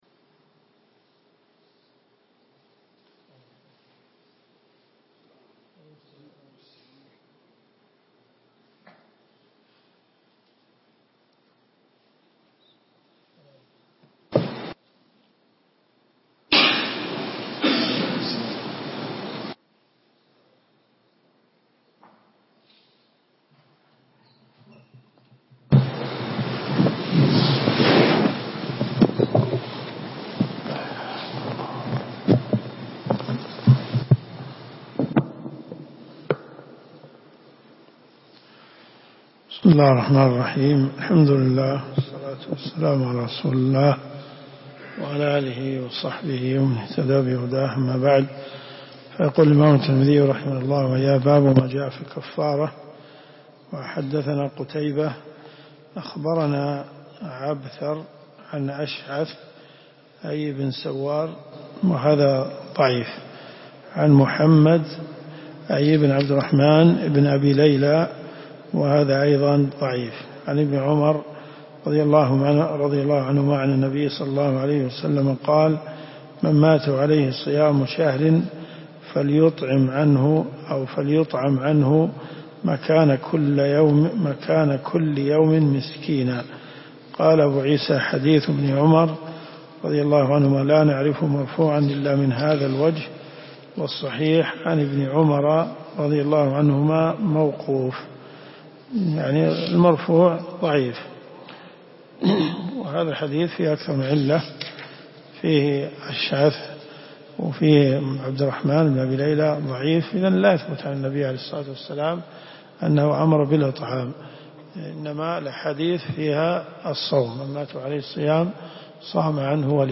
الرئيسية الكتب المسموعة [ قسم الحديث ] > جامع الترمذي .